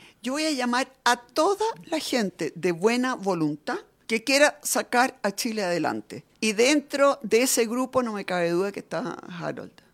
En entrevista con Radio Genial de Coyhaique, Matthei valoró el desempeño del expresidente de la ANFP durante el cara a cara televisado, destacando que “su tono fue esperanzador, con mirada de futuro”.